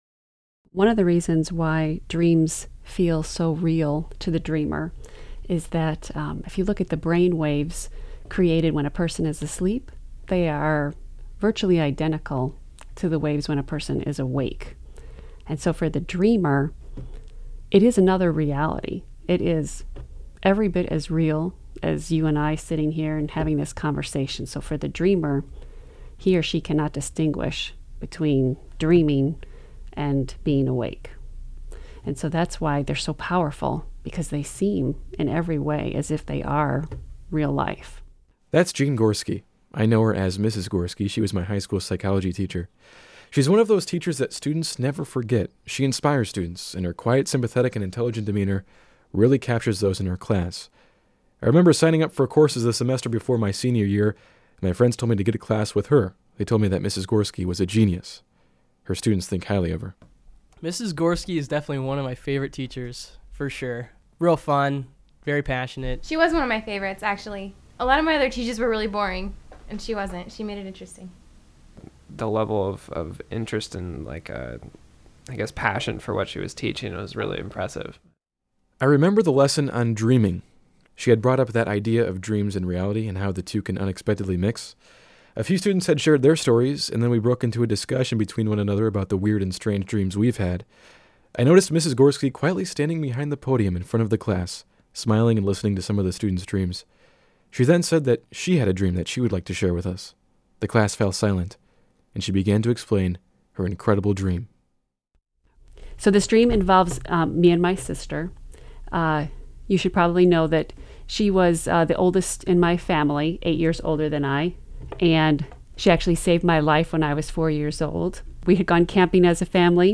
Sound Instillation 1.mp3